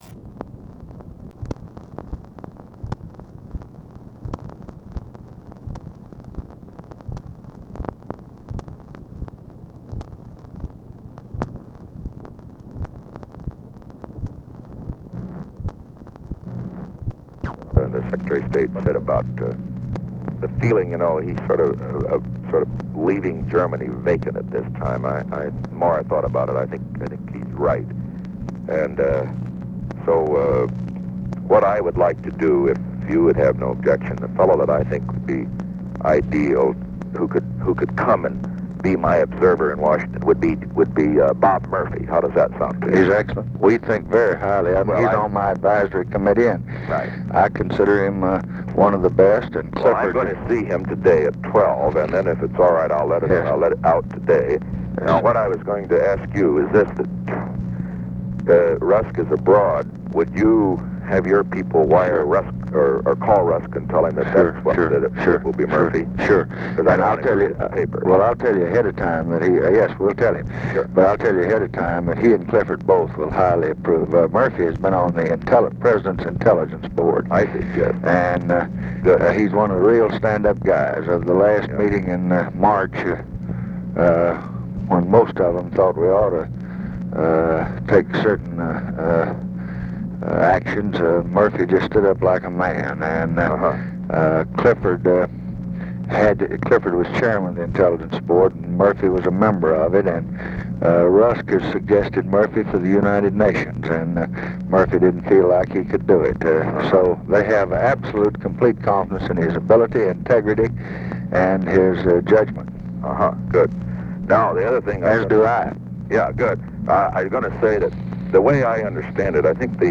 Conversation with RICHARD NIXON, November 14, 1968
Secret White House Tapes